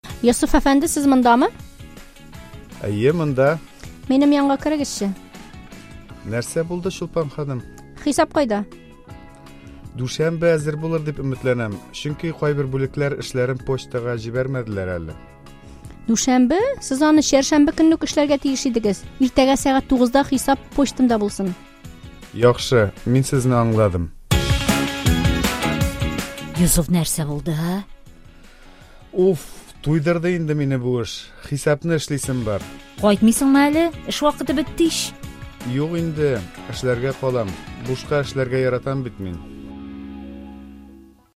Диалог: Уф, туйдырды!